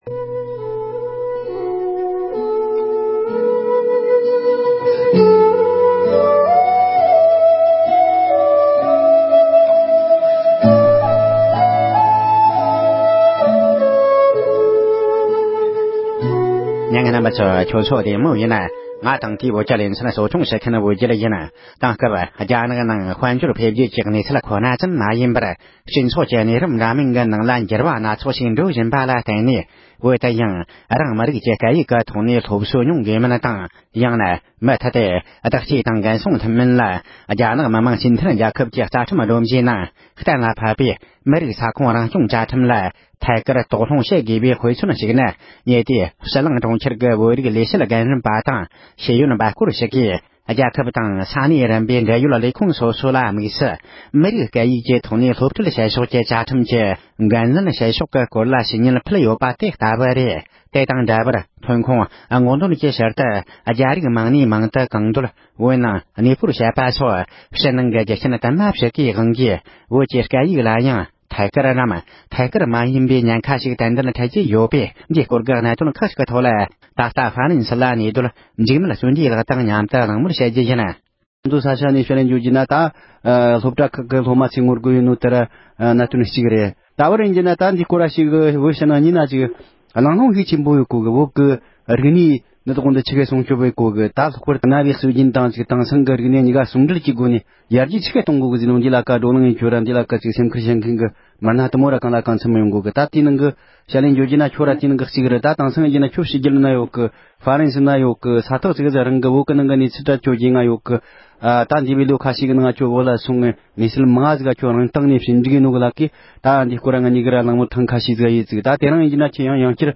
བོད་ཀྱི་སྐད་ཡིག་སྲུང་སྐྱོབ་དང་དེའི་ཉེན་ཁའི་རང་བཞིན་ཐད་འབྲེལ་ཡོད་མི་སྣ་དང་གླེང་མོལ།
སྒྲ་ལྡན་གསར་འགྱུར།